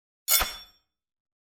SWORD_13.wav